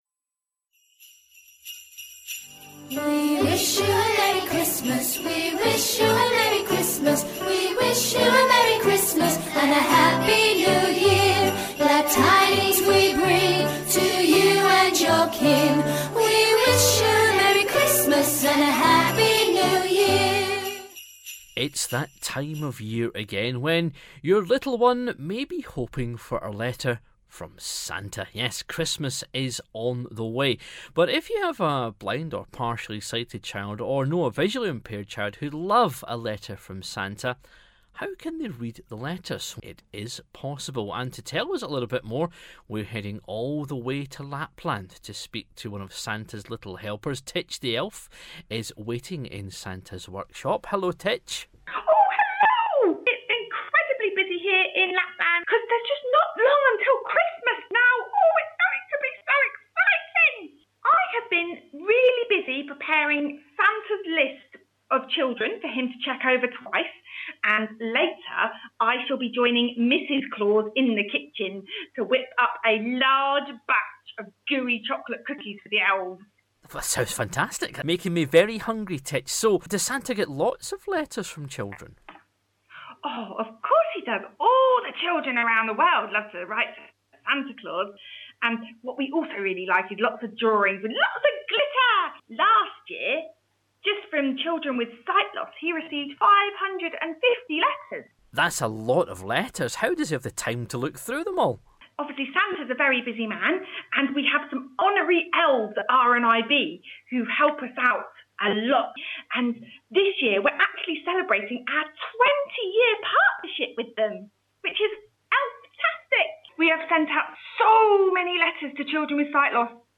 made a special call to Lapland to speak to Titch The Elf to find out what Santa needs to know, so he can bring a big smile to their faces.